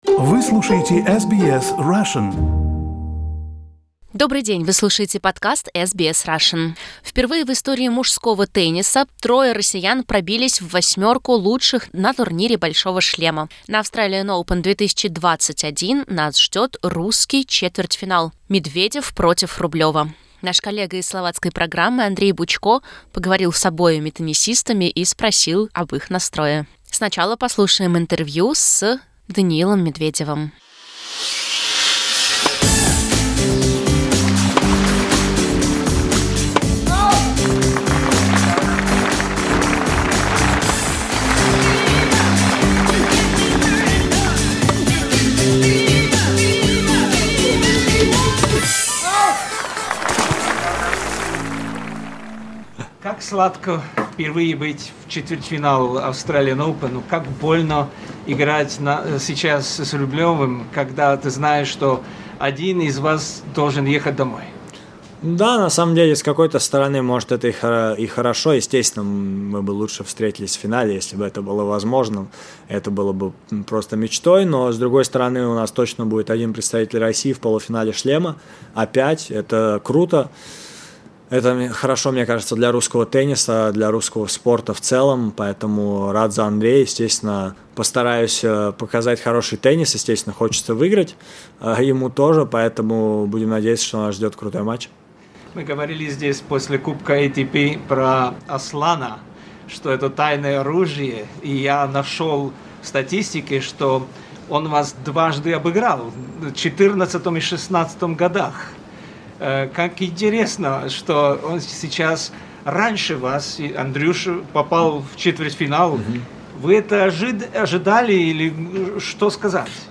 Exclusive interview in Russian only.